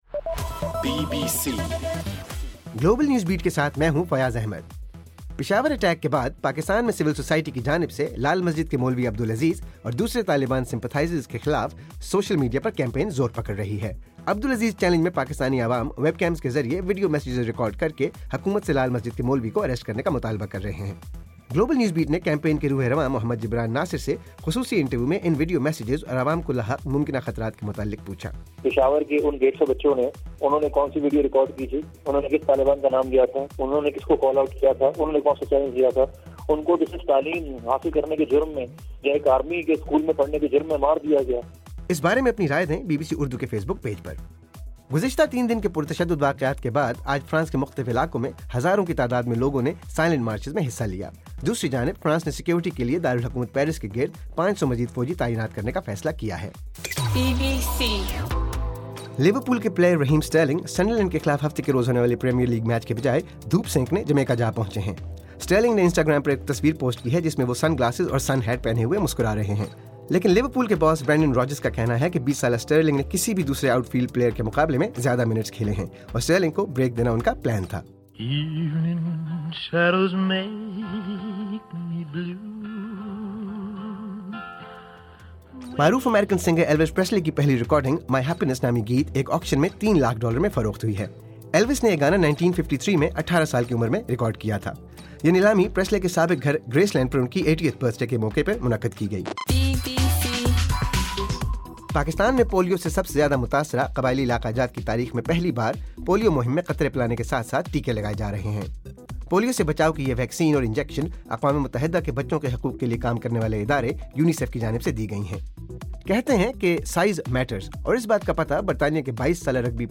جنوری 10: رات 12 بجے کا گلوبل نیوز بیٹ بُلیٹن